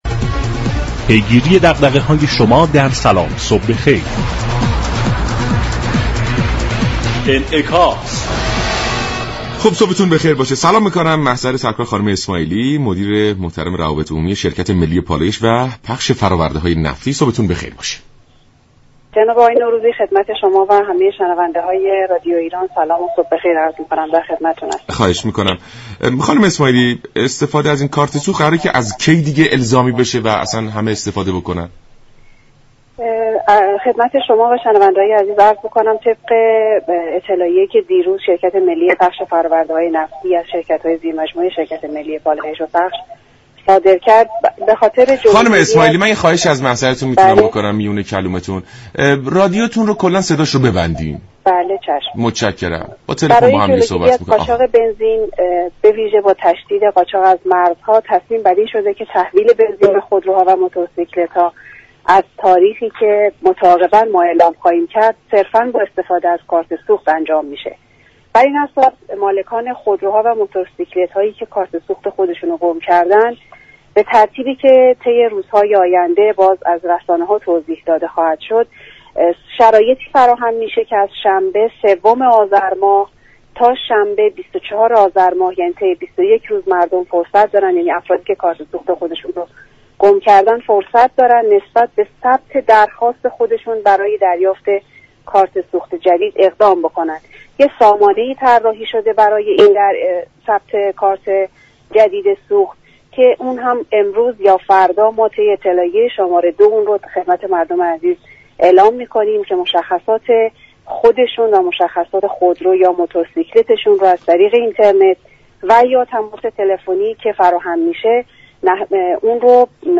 در گفت و گو با برنامه «سلام صبح بخیر»